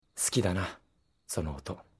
take a listen to the sweet sounds of Miki; as Yougi Kudou, from the anime Weiss Kreuz.